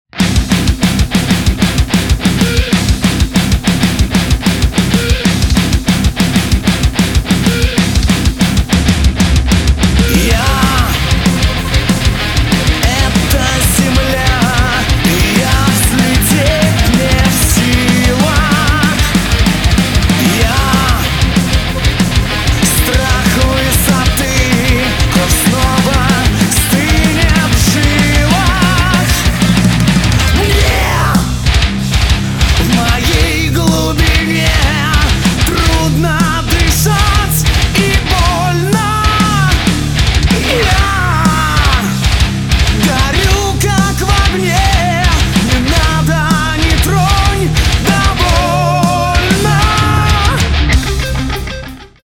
Metal (Power, MDM, Metalcore)